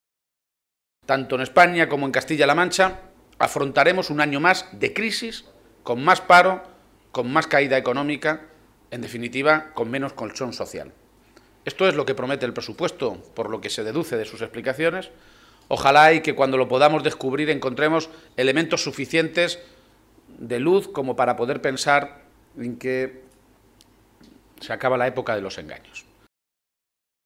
El secretario general del PSOE de Castilla-La Mancha, Emiliano García-Page, ha comparecido hoy ante los medios de comunicación minutos antes de la celebración del primer Comité Regional después de Décimo Congreso que le eligió con un 95 por ciento de los votos nuevo máximo dirigente de los socialistas de Castilla-La Mancha.